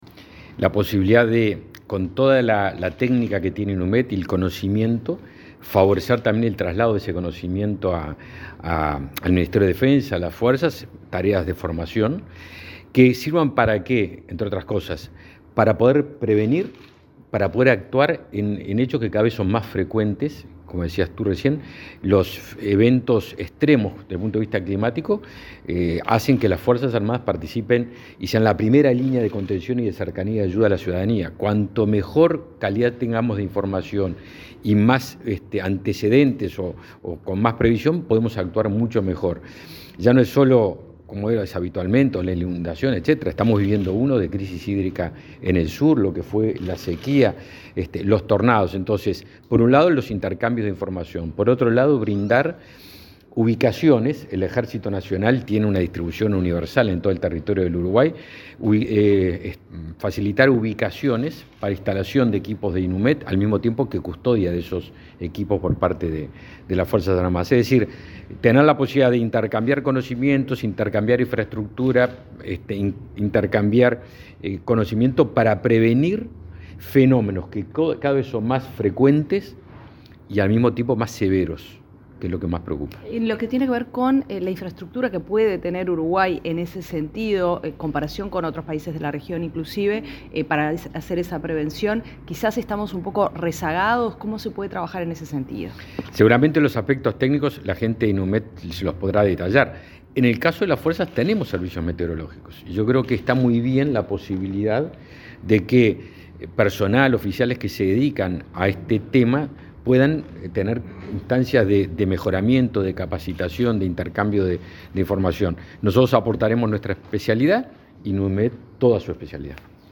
Declaraciones del ministro de Defensa Nacional, Javier García
Luego el secretario de Estado dialogó con la prensa.